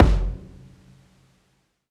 live_stomp_kik.wav